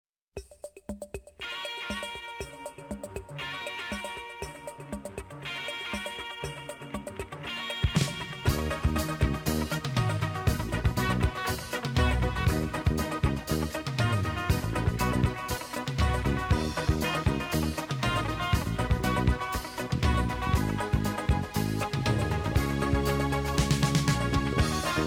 胡散臭さ満載の 過激プログレバンド。